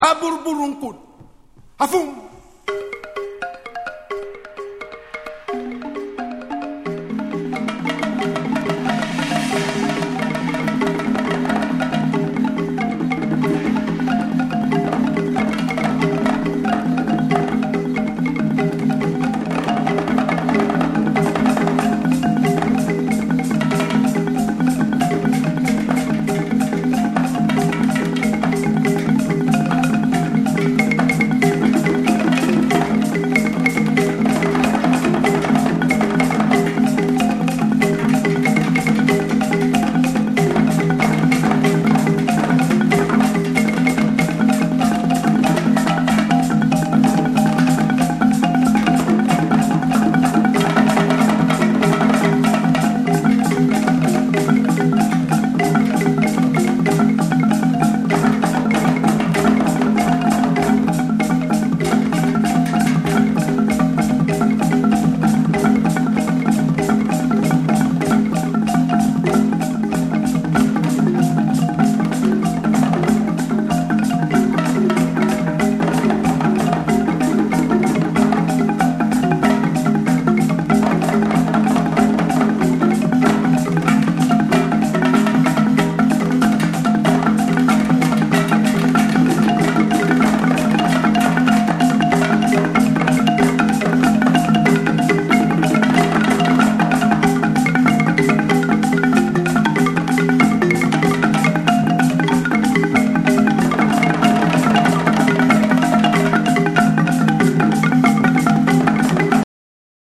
カメルーン出身の3兄弟によって結成されたパーカッション・トリオによる、圧倒的なグルーヴとリズムの魔術が詰まったアルバム。